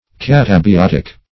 Catabiotic \Cat`a*bi*ot"ic\, a.